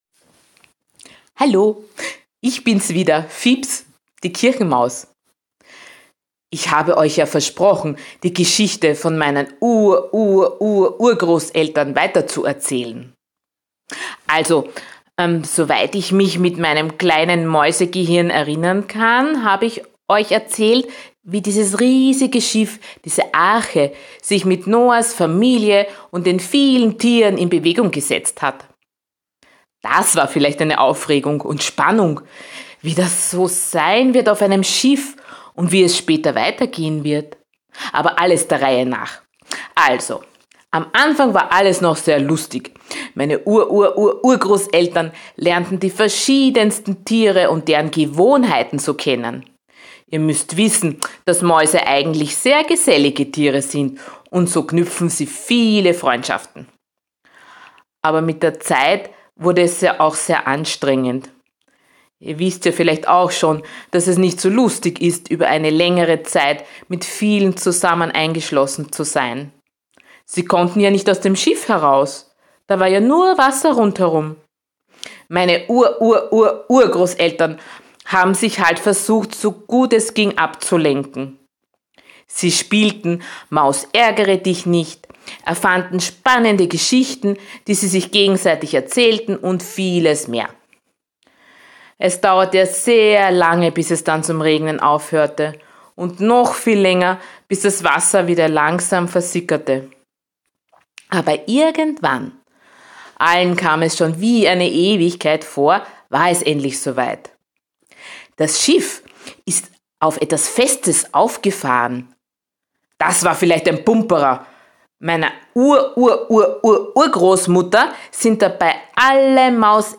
Geschichte: Arche-Noah 2. Teil